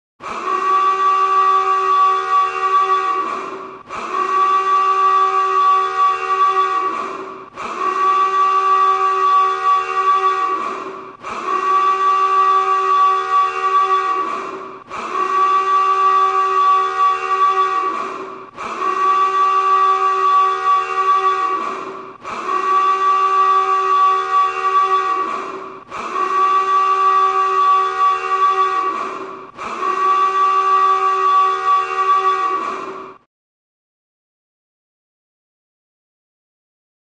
Alarm 3, Interior, Submarine - Military, Giant Low Pitched Aa-ooo-gah Type Warning Alarm with Large Reverb.